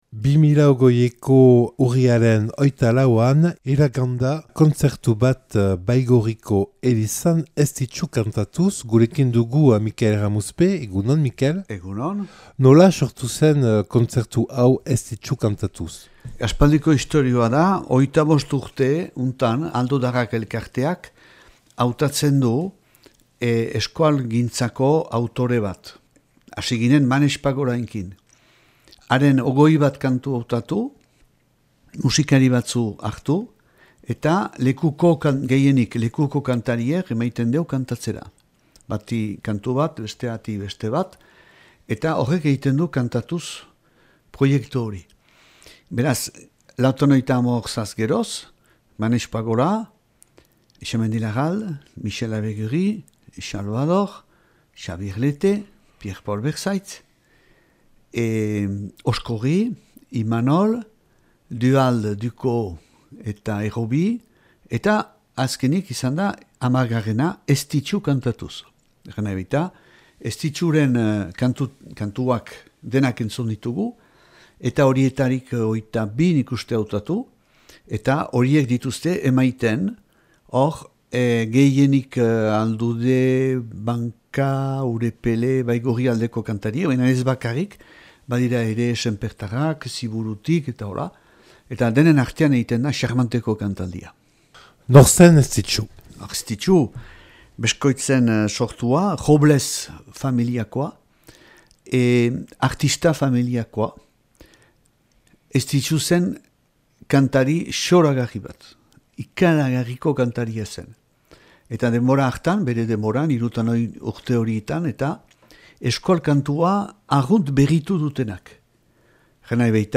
Baigorriko elizan grabatua 2020ko urriaren 24ean.